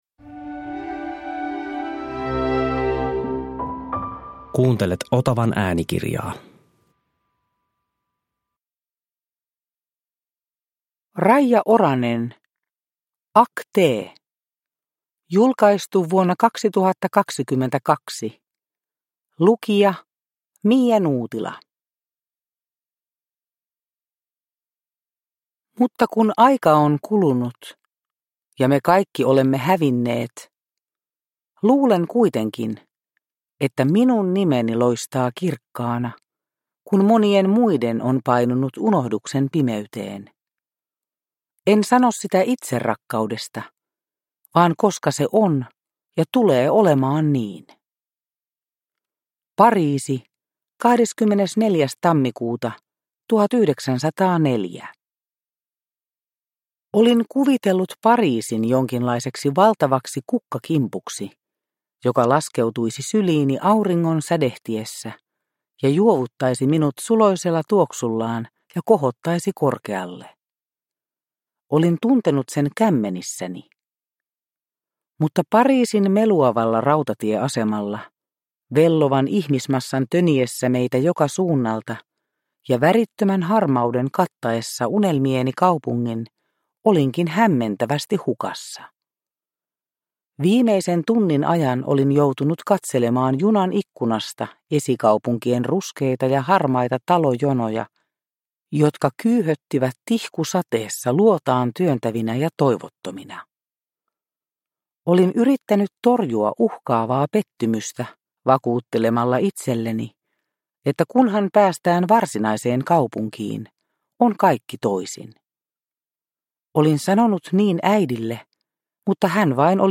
Ackté! – Ljudbok – Laddas ner